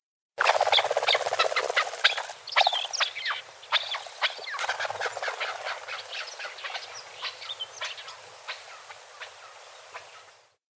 Звуки кеклика
Звуки кекликов